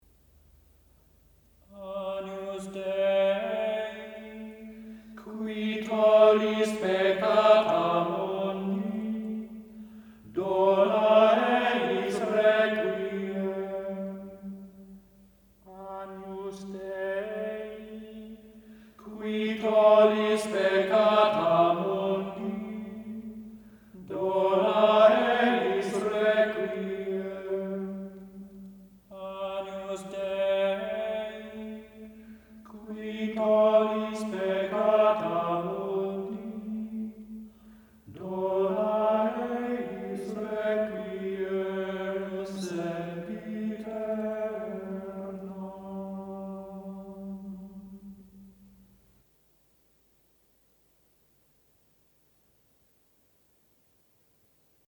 Activité d'écoute [La musique médiévale religieuse]
4__Missa_da_Requiem_gregoriienne_-_Agnus_Deii.mp3